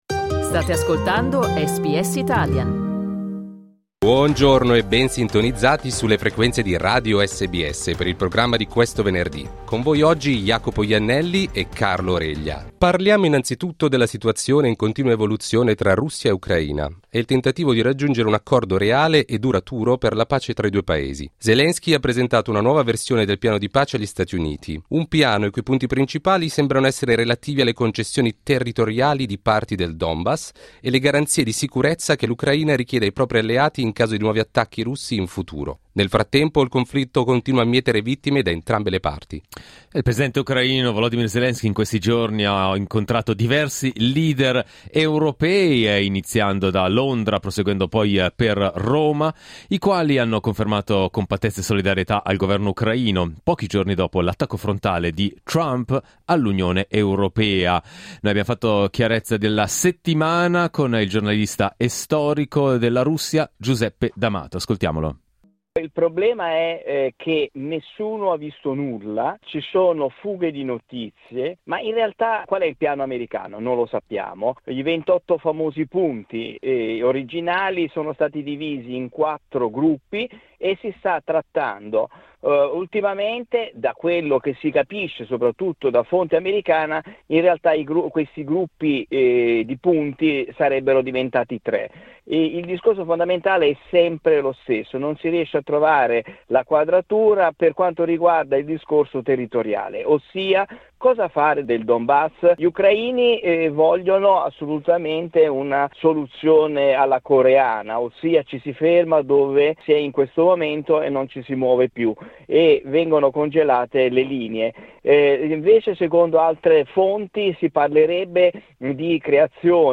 Il presidente ucraino, Volodymyr Zelensky, continua a esprimere scetticismo sul compromesso territoriale proposto dagli USA per il Donbass nell'ambito dei negoziati per la pace in Ucraina. Ascolta l'analisi del giornalista